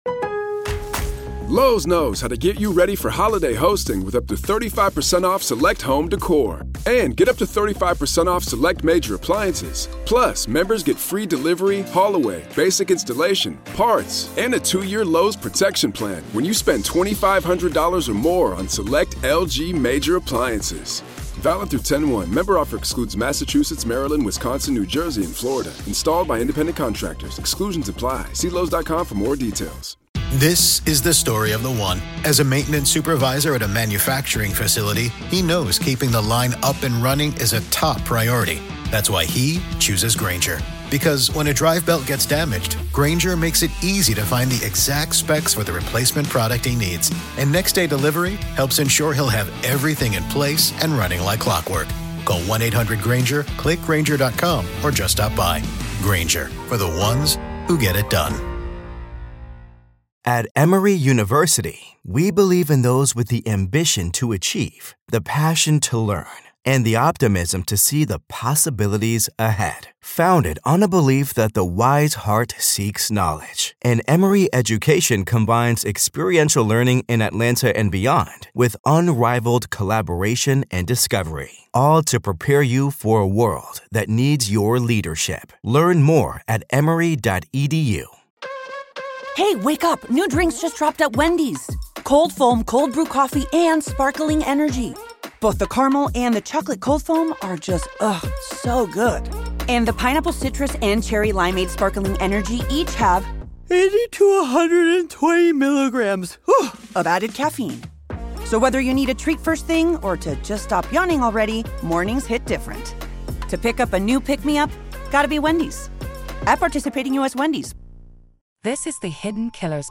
in Stamford Superior Court in Co...